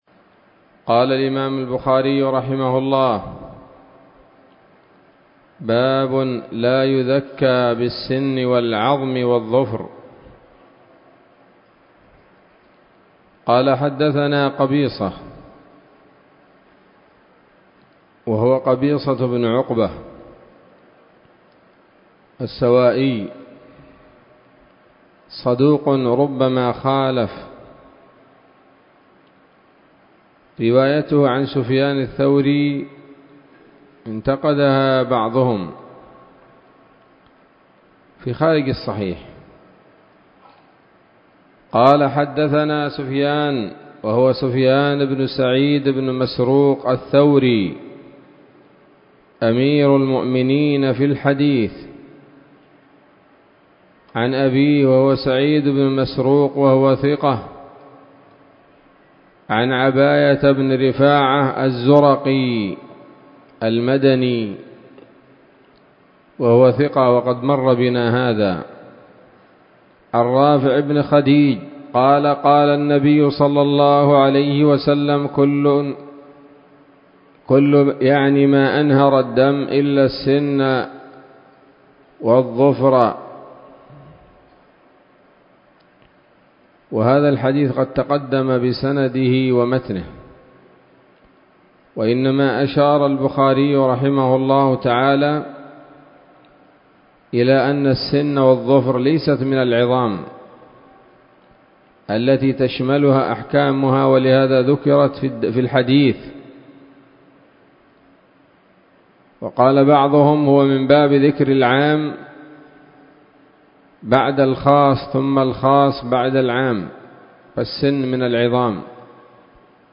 الدرس السابع عشر من كتاب الذبائح والصيد من صحيح الإمام البخاري